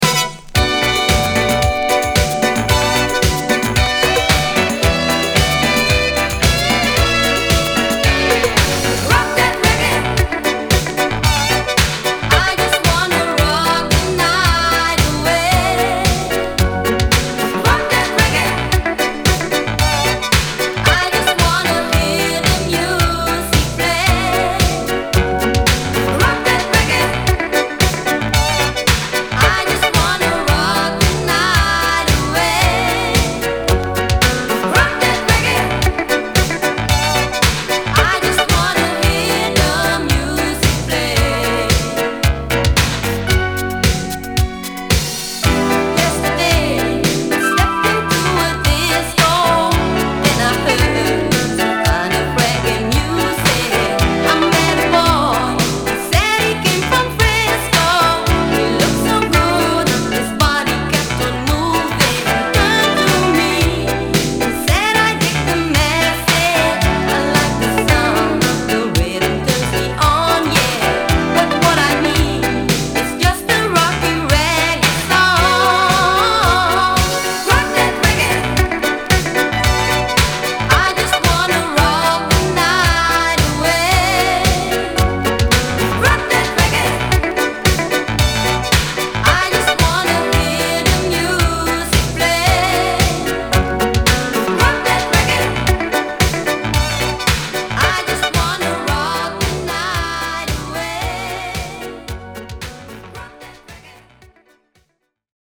Dutch Electro Disco Boogie!!